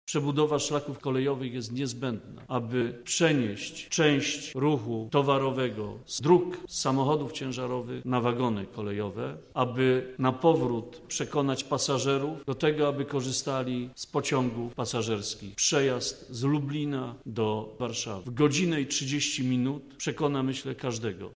K26_22EDE614092D4FD4803E61BD1FD7607C – mówi Andrzej Adamczyk, minister infrastruktury i budownictwa.